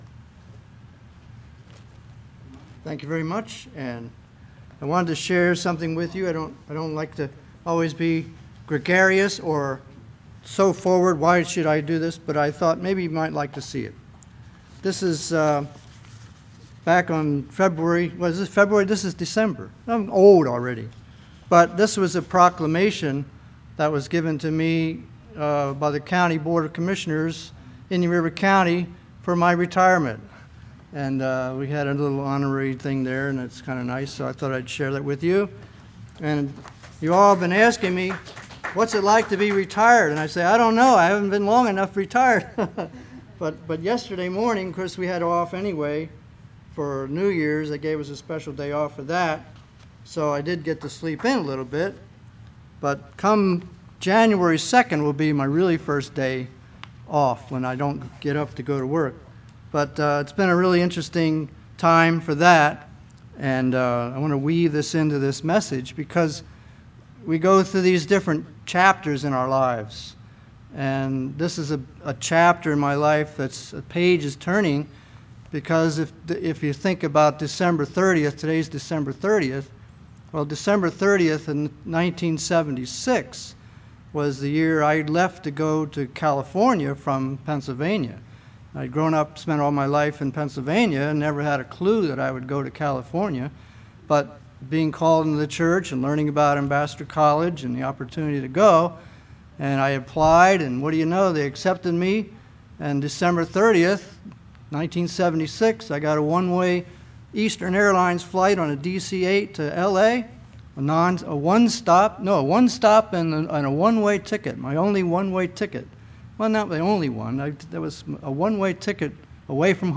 Given in Vero Beach, FL